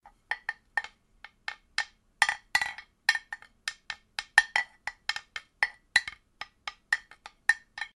ear candy porcelain mug hits.mp3
Recorded with a Steinberg Sterling Audio ST66 Tube, in a small apartment studio.
.WAV .MP3 .OGG 0:00 / 0:08 Type Mp3 Duration 0:08 Size 1,34 MB Samplerate 44100 Hz Bitrate 96 kbps Channels Stereo Recorded with a Steinberg Sterling Audio ST66 Tube, in a small apartment studio.
ear_candy_porcelain_mug_hits_2rk.ogg